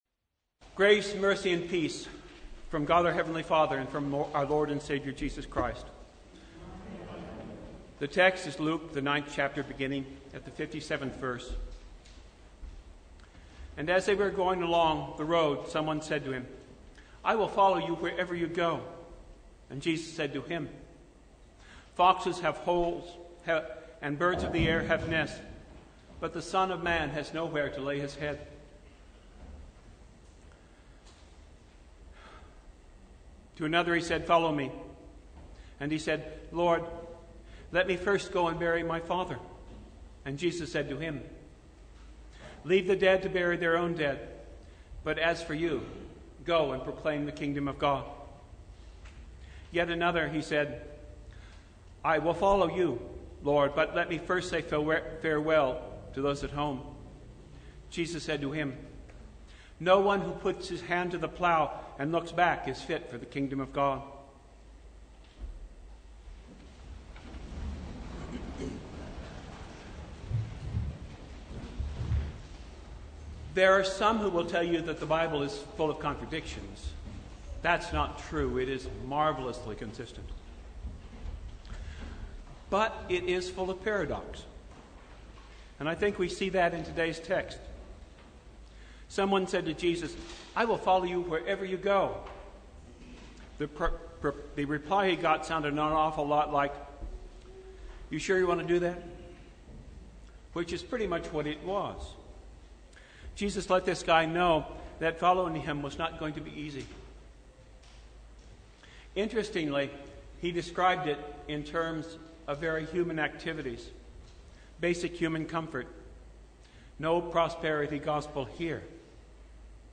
Sermon from The First Sunday in Apostles’ Tide (2022)